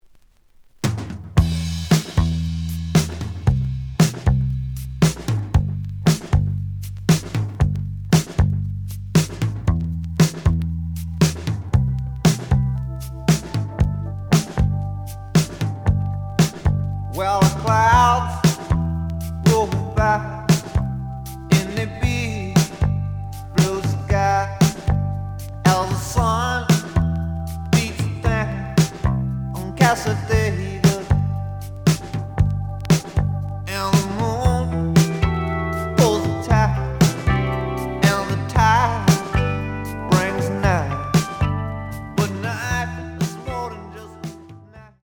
試聴は実際のレコードから録音しています。
●Genre: Rock / Pop
●Record Grading: VG+~EX- (盤に若干の歪み。多少の傷はあるが、おおむね良好。)